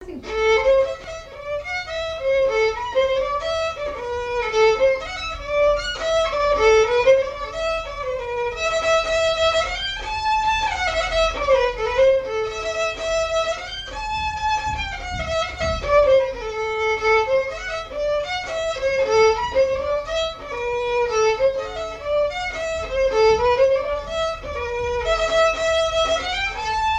danse : branle : avant-deux ;
airs de danses et chansons traditionnelles
Pièce musicale inédite